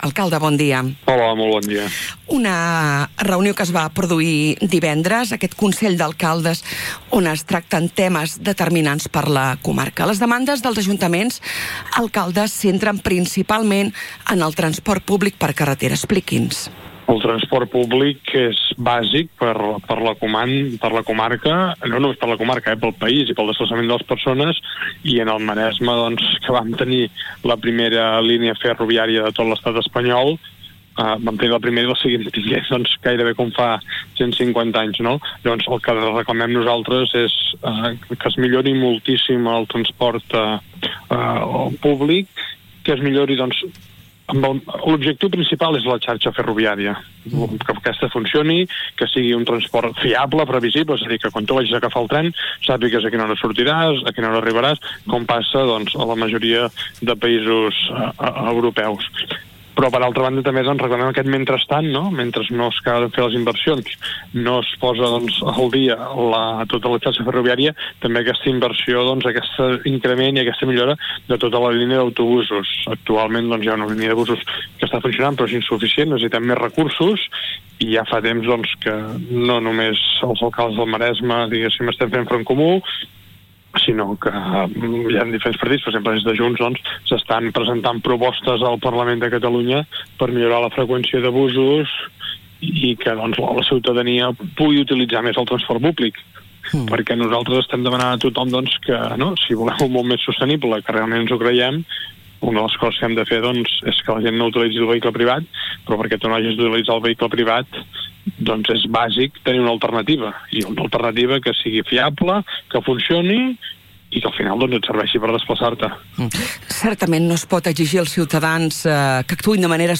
A continuació podeu recuperar íntegrament l’entrevista a l’alcalde Marc Buch al programa a l’FM i + de RCT: